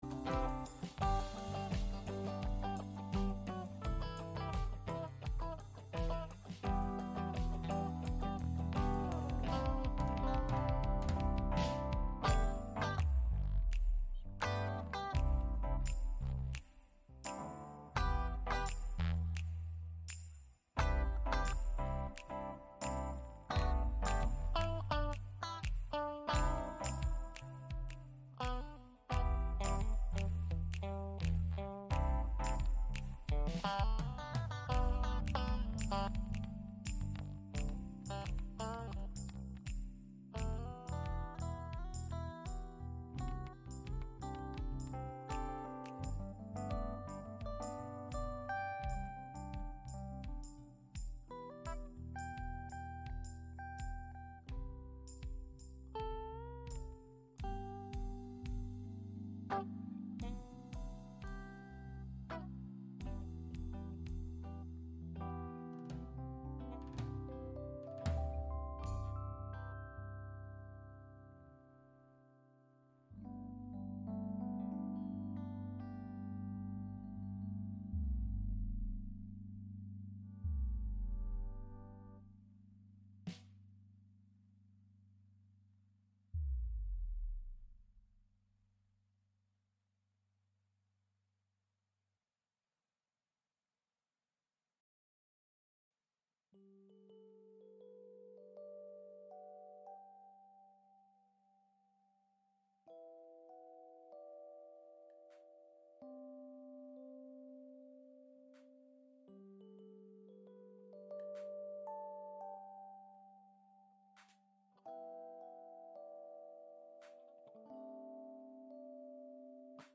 Gottesdienst am 26. Mai 2024 aus der Christuskirche Altona